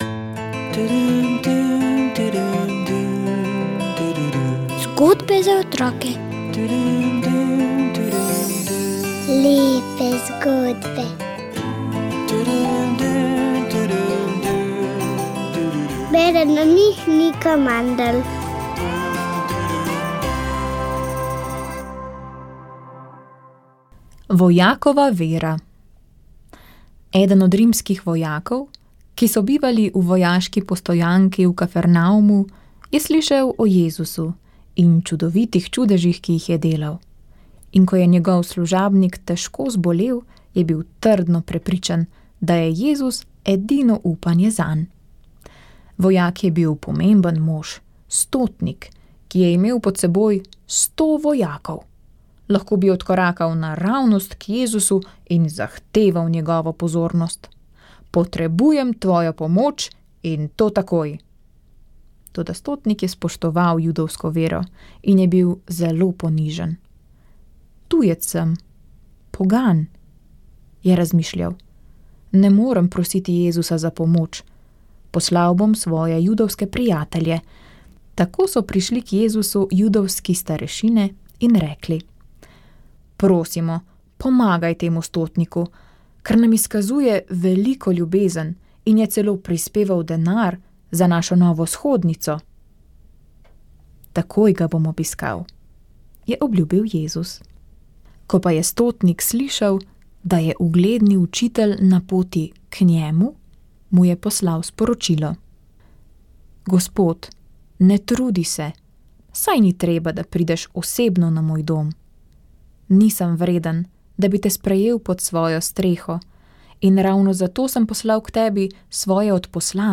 Molile so redovnice - Hčere krščanske ljubezni (Usmiljenke).